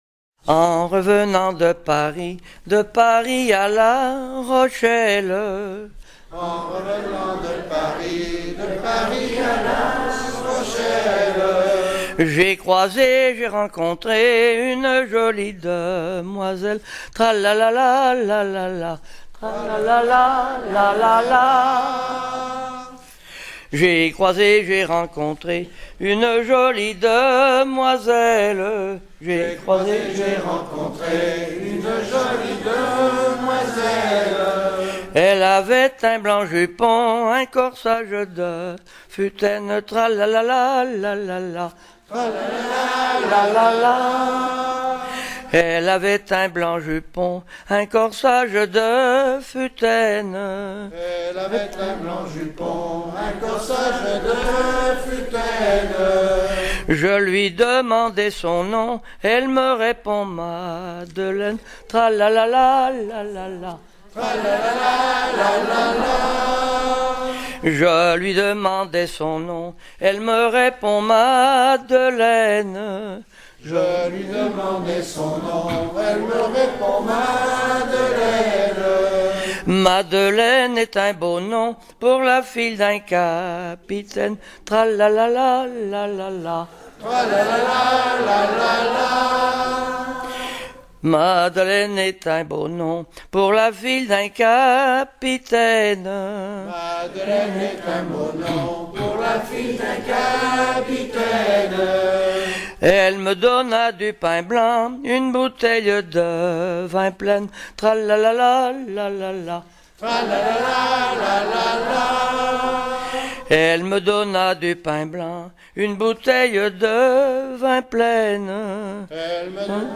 Incipit du refrain Tra la la la, la la la
Genre laisse
Pièce musicale éditée